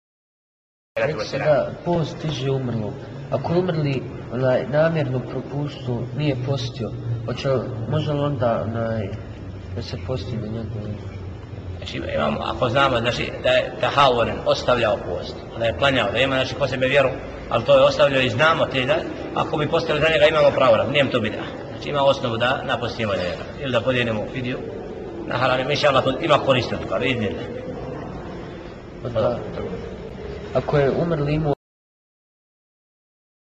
Preuzeto iz video predavanja